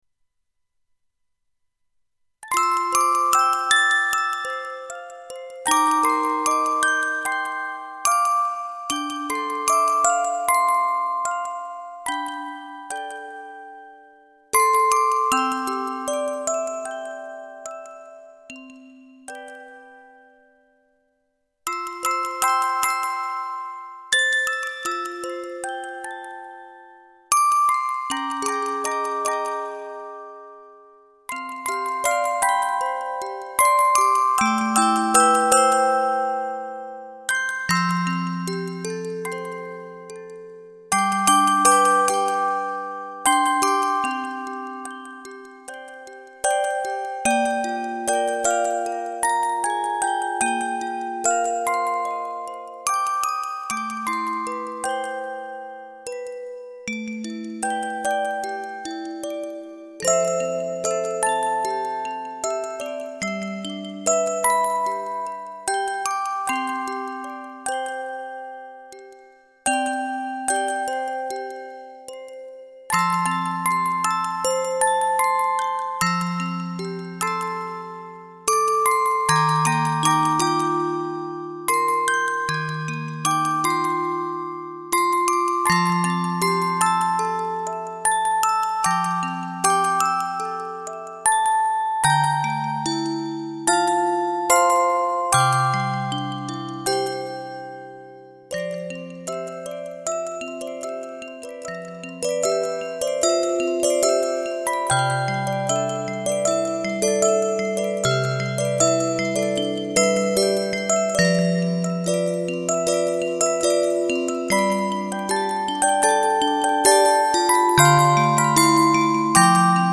オルゴール。即興。ディレイがうるさい。